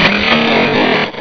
pokeemerald / sound / direct_sound_samples / cries / flygon.aif
-Replaced the Gen. 1 to 3 cries with BW2 rips.